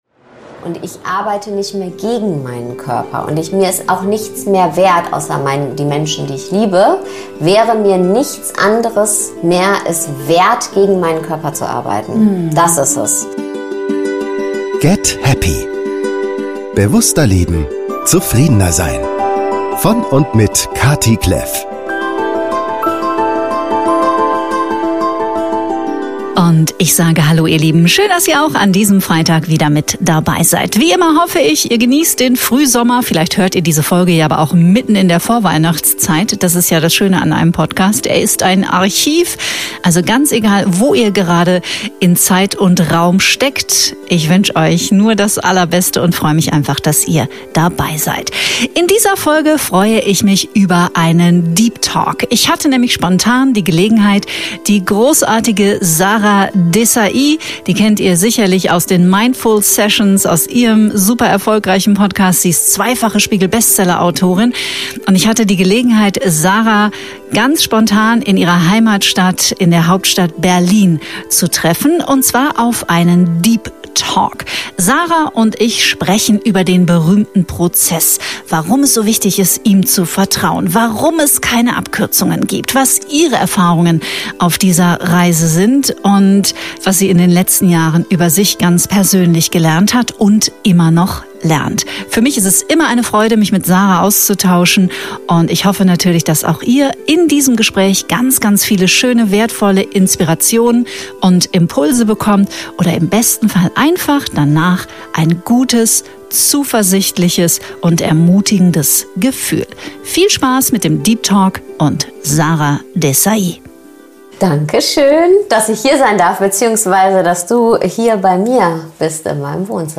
In dieser Folge führe ich ein tiefes Gespräch mit Ausbilderin
Viel Freude mit unserem Deep Talk!